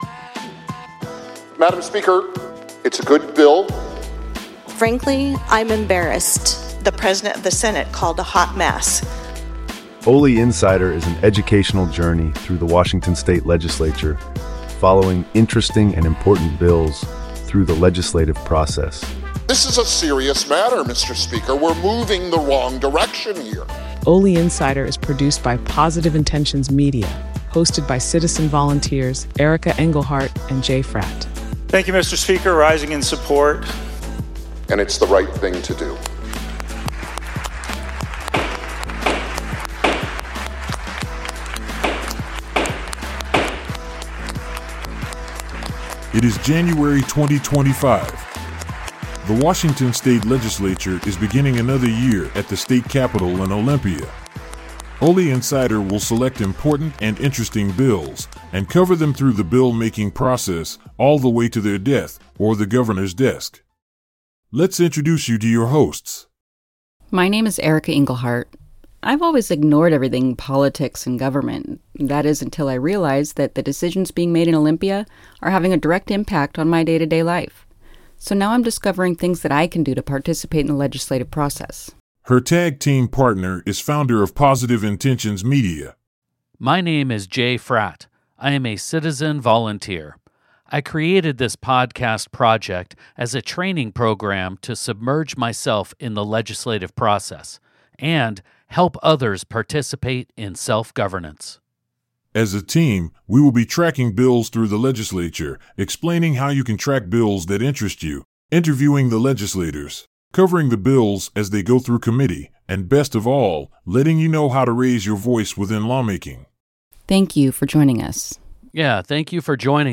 Intro and Outro Music Credits